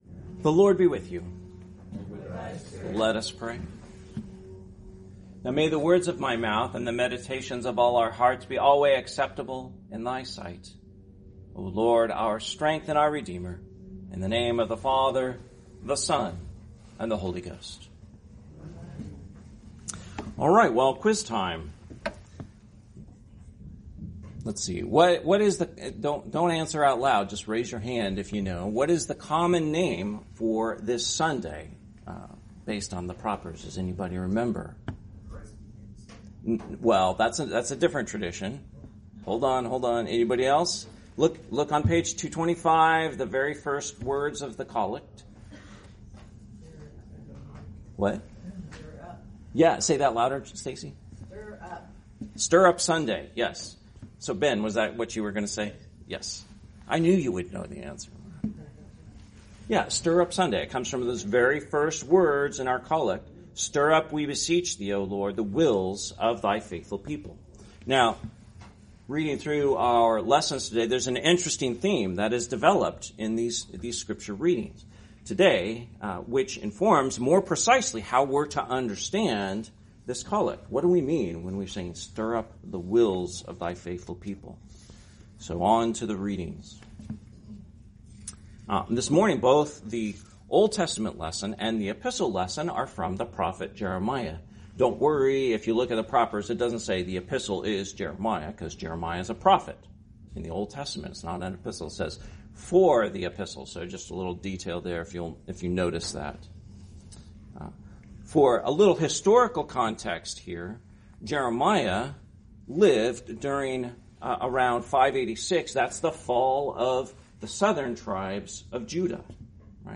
Sermon, Sunday Next Before Advent, 2024